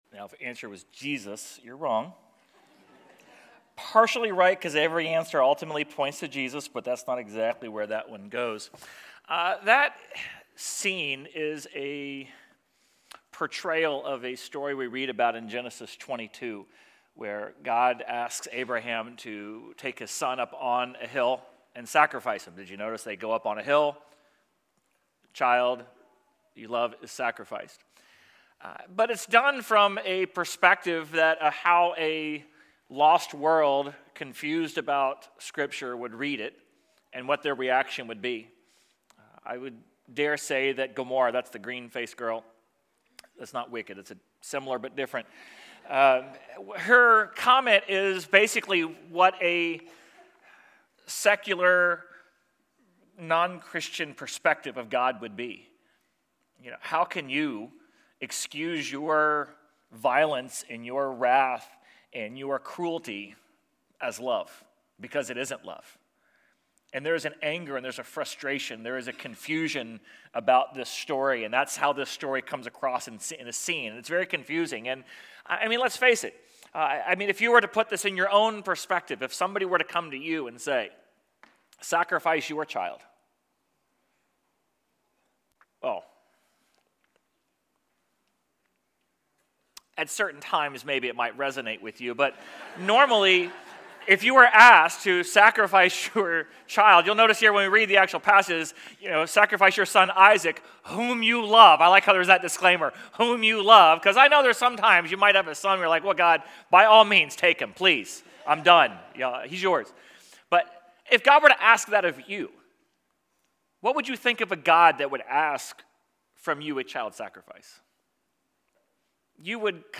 Sermon_3.22.26.mp3